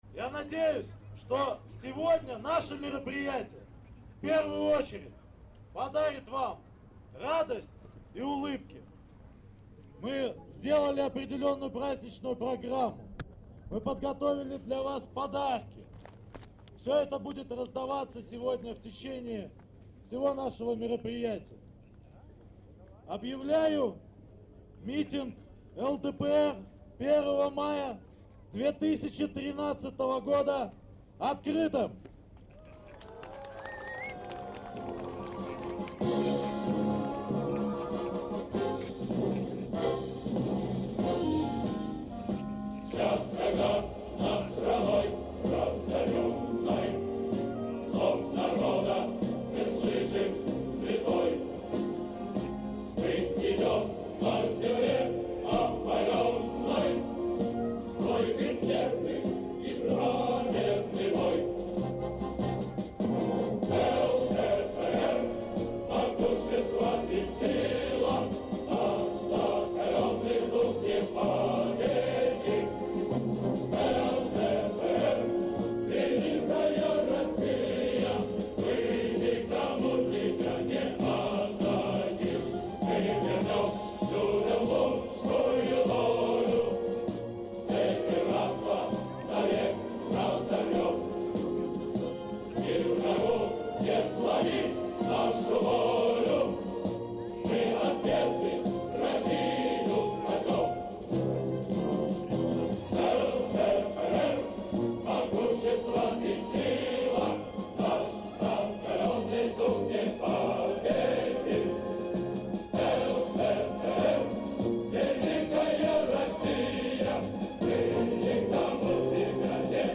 am nächsten Tag dann der erste Mai. dort waren vielleicht diejenigen, die die gestern noch die Fragen nach der Heimat gestellt hatten. hören sie selbst den Sprachvergleich.
Schande über alle, die in Inhalt, Gestus und Intonation die russische Sprache so hinabziehen.